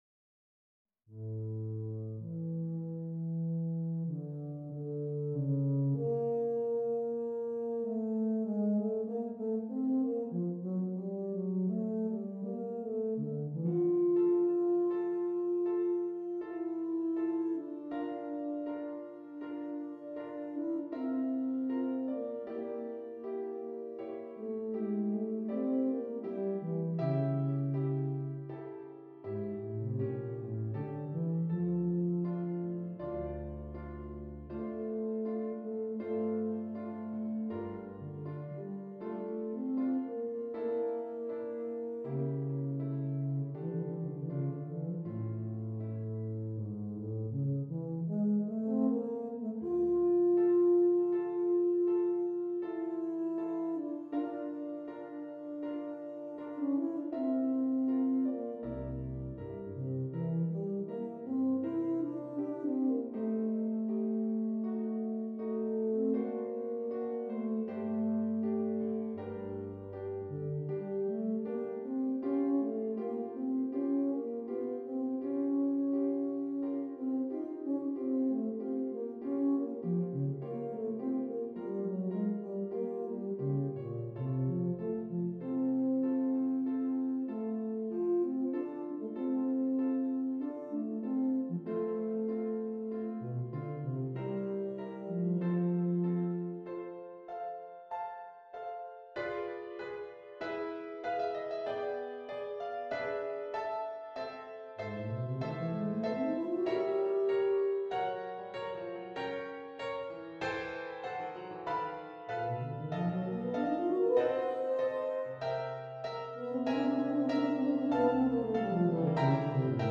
Tuba and Keyboard